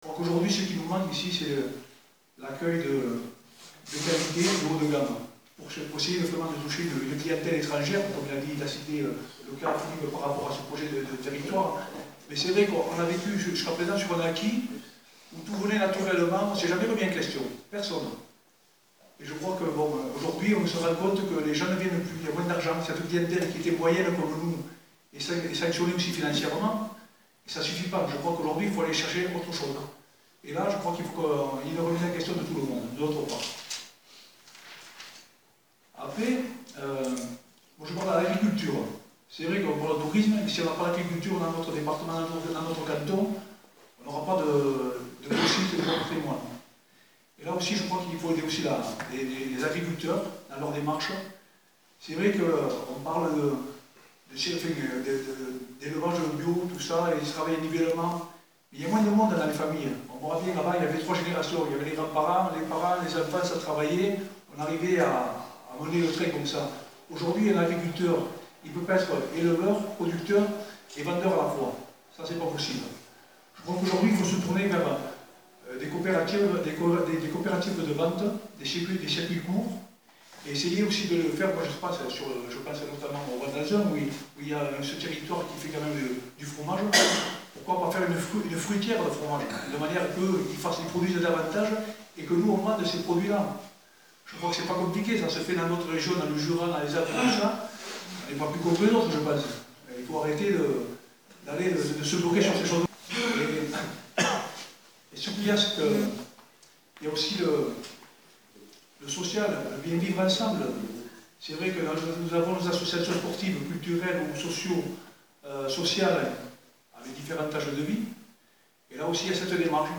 Argelès-Gazost : Réunion publique du binôme Chantal Robin-Rodrigo-Louis Armary (Majorité départementale – PRG) - [Site d'informations indépendant, sur les communes des Hautes Vallées des Gaves dans le 65]